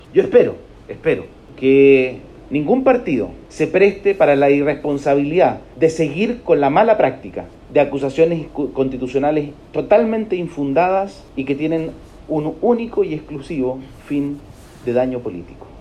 En La Moneda, el subsecretario del Interior, Juan Francisco Galli, hizo un llamado a no utilizar la institucionalidad para fines políticos, como ha sucedido durante la administración del presidente Piñera.